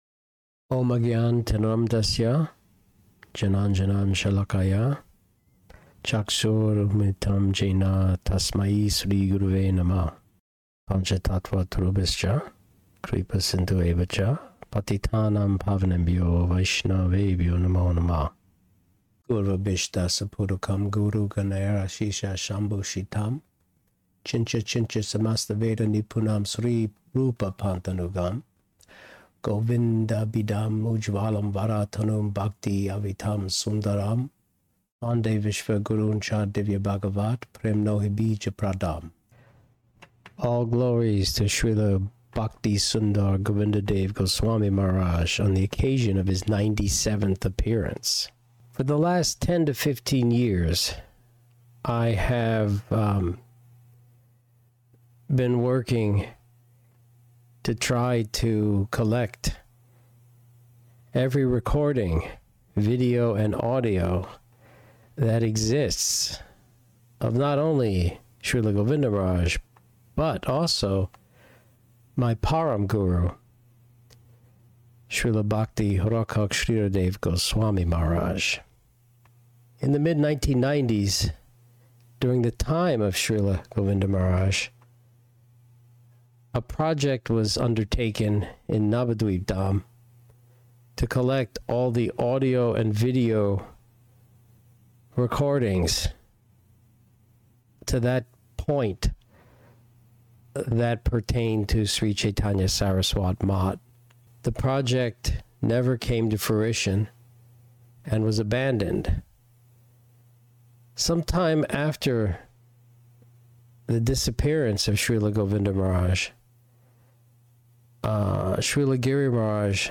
audio lecture